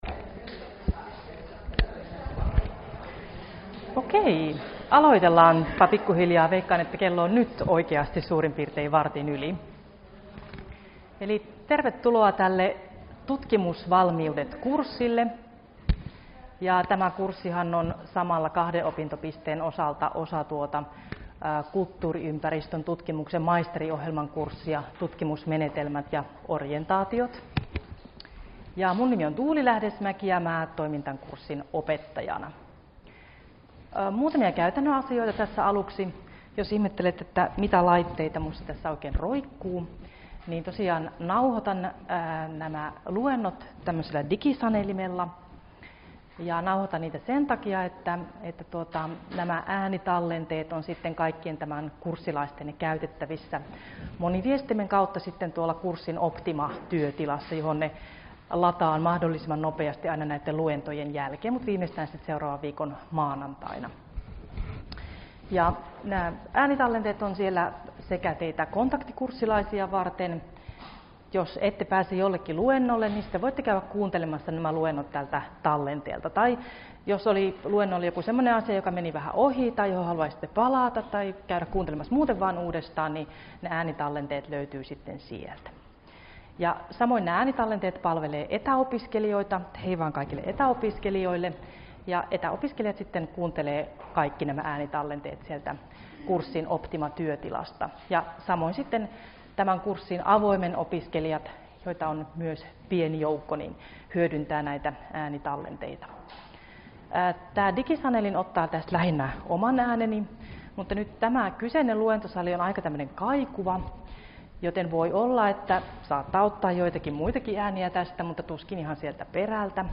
Luento 1 - Käsitteet ja osa-alueet — Moniviestin